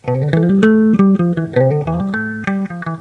可循环的吉他片段直接从处理器到设备，随后转换为WAV。在各种USB类设置中测试DS40，以确定设备是否可以作为廉价的USB接口使用。